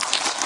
PixelPerfectionCE/assets/minecraft/sounds/mob/spider/say1.ogg at mc116